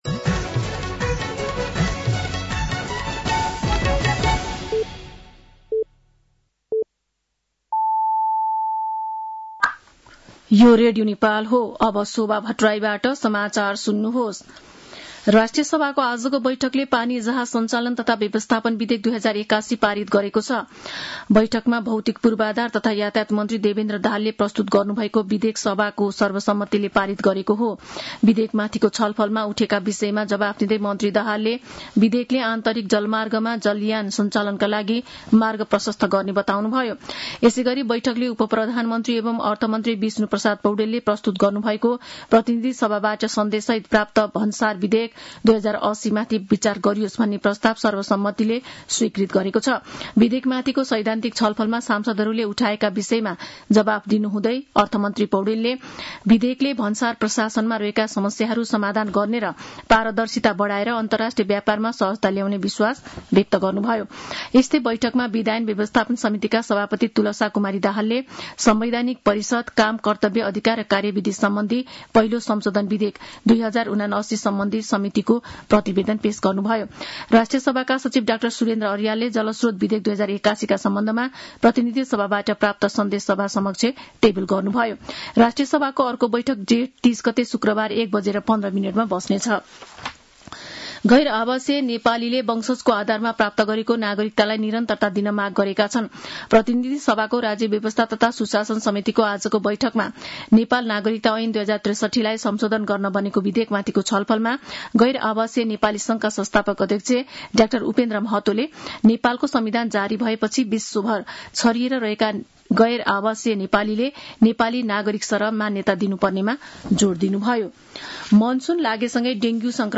साँझ ५ बजेको नेपाली समाचार : २६ जेठ , २०८२
5.-pm-nepali-news-1.mp3